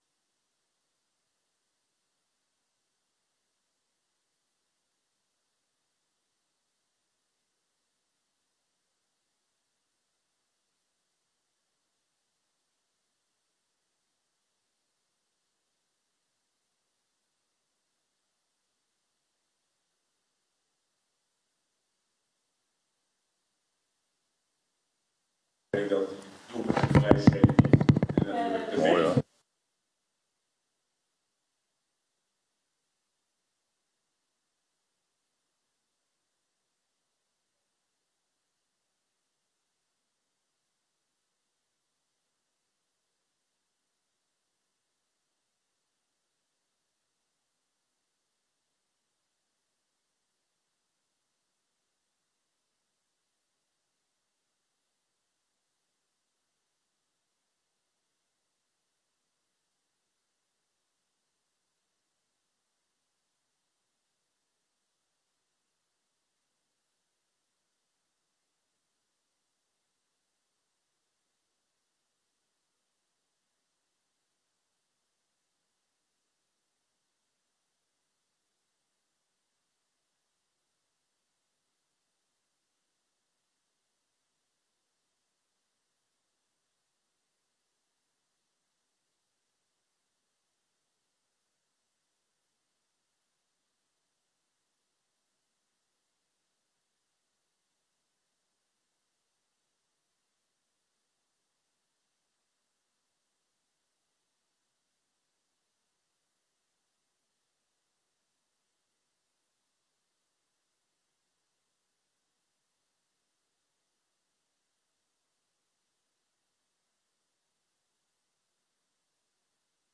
Download de volledige audio van deze vergadering
Locatie: Razende Bol